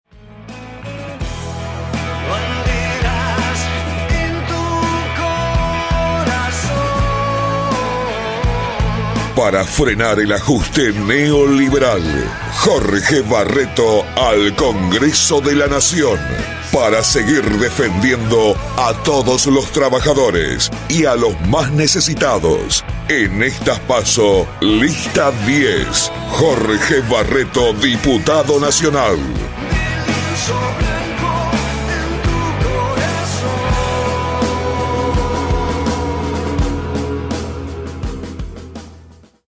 canción de campaña